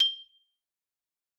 Xylo_Medium_G6_ff_01_far.wav